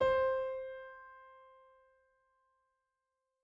SoftPiano
c4.mp3